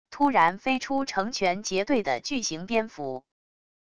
突然飞出成全结队的巨型蝙蝠wav音频